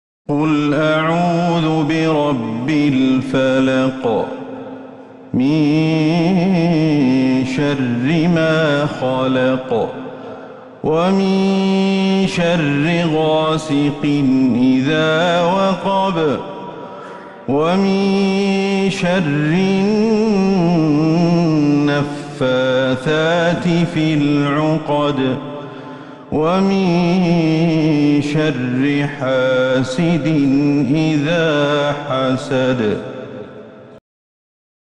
سورة الفلق Surat Al-Falaq > المصحف المرتل من المسجد النبوي > المصحف - تلاوات الشيخ أحمد الحذيفي